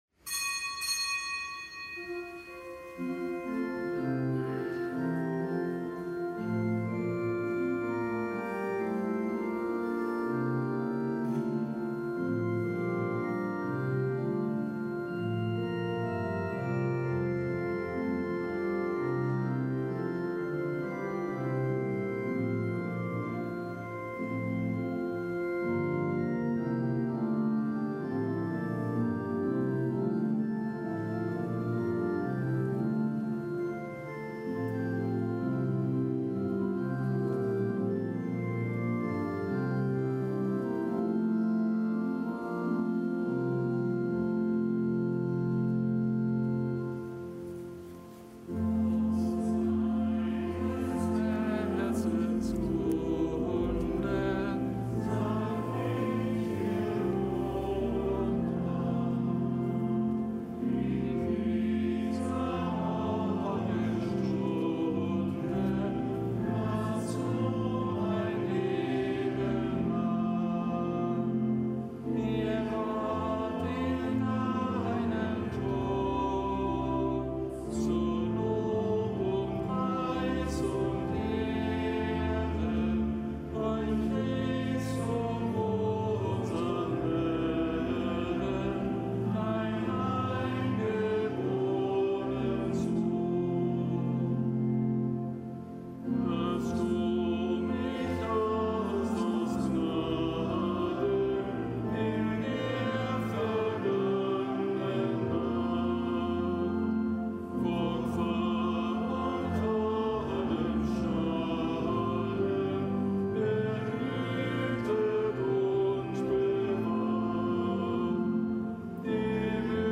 Kapitelsmesse am Dienstag der dreiundzwanzigsten Woche im Jahreskreis
Kapitelsmesse aus dem Kölner Dom am Dienstag der dreiundzwanzigsten Woche im Jahreskreis; Nichtgebotener Gedenktag des Heiligen Petrus Claver, Priester.